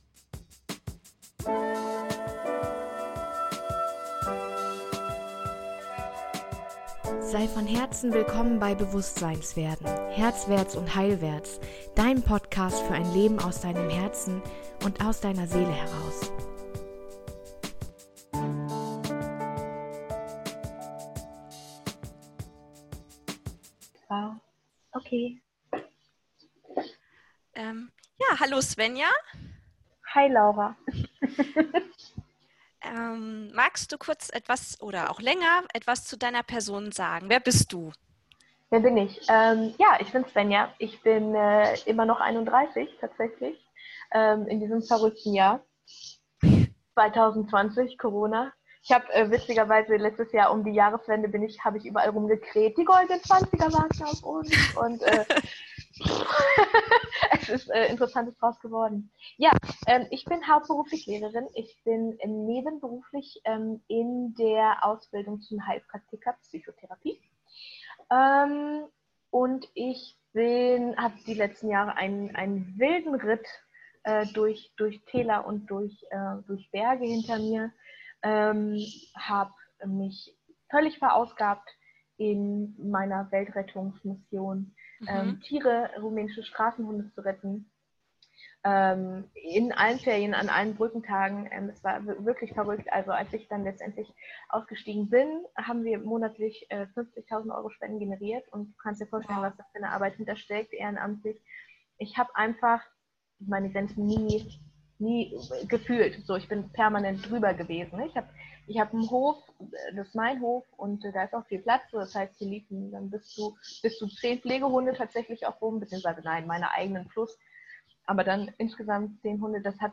Ein Gespräch über das Leben und Sterben.